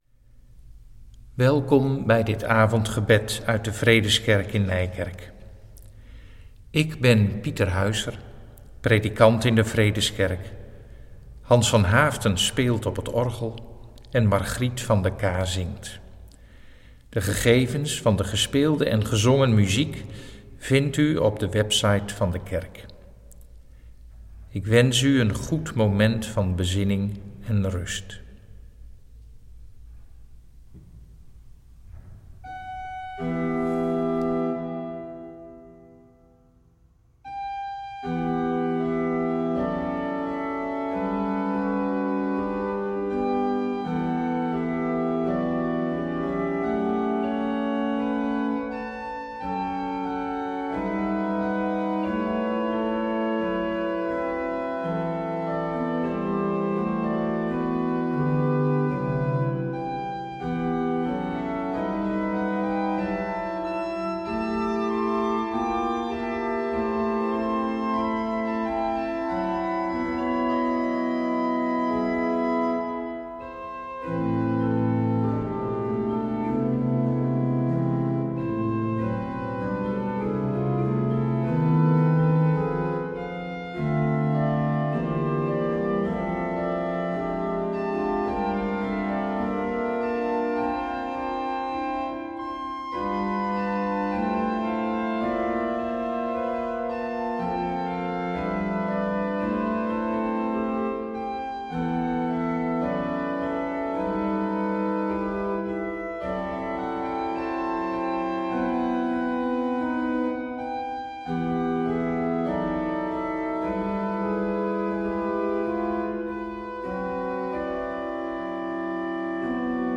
Zij zullen van tevoren opgenomen worden.
We bidden, afgesloten met het Onze Vader.